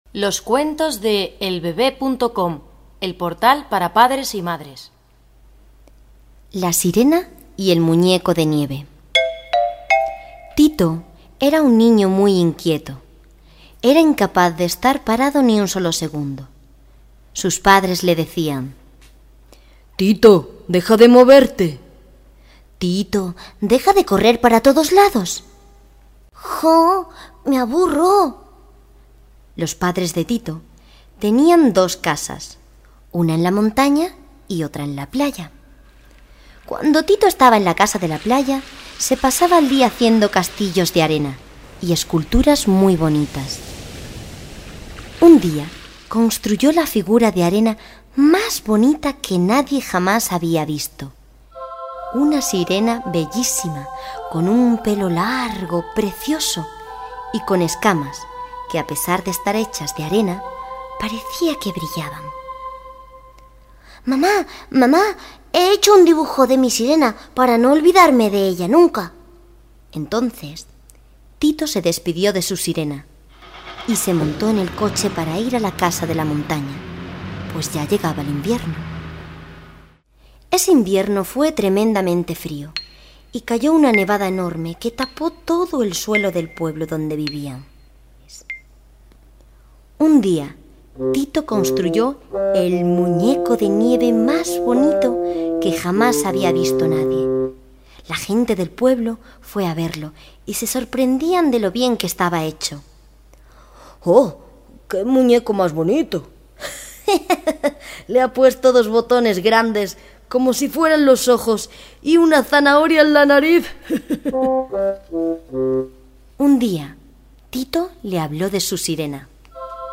Cuentos infantiles